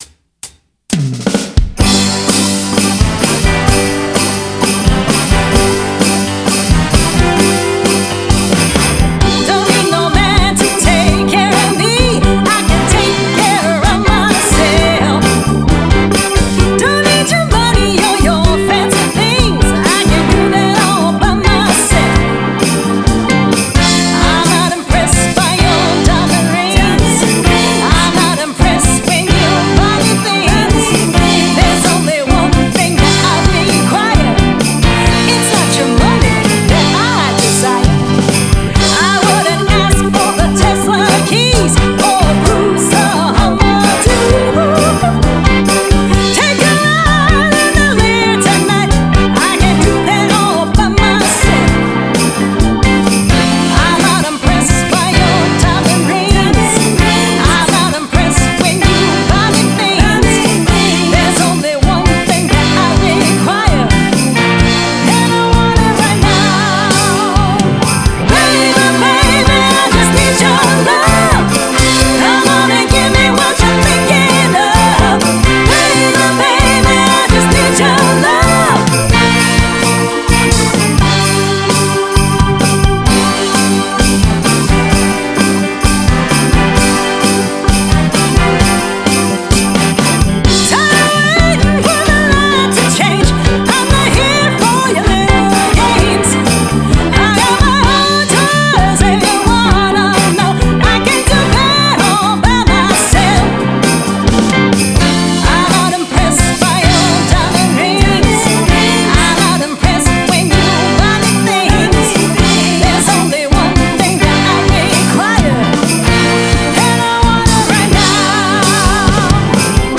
Lead and back up vocals
Keys, horns, vibes, the kitchen sink